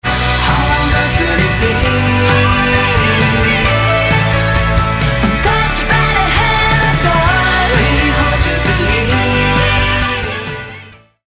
Gitarre
GenrePop
Feinster schwedischer Pop-Rock-Sound.